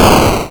lose.wav